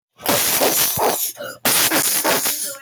Gun Shot Sound Button - Free Download & Play